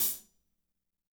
-16  HAT 1-L.wav